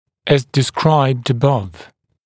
[æz dɪ’skraɪbd ə’bʌv][эз ди’скрайбд э’бав]как было отмечено выше